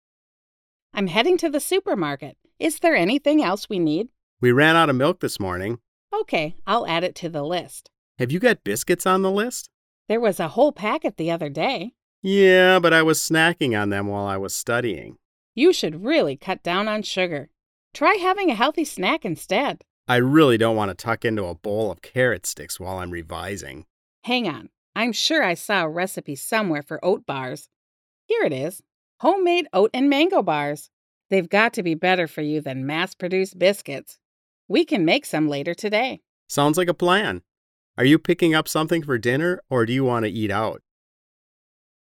RH5 4.1_conversation.mp3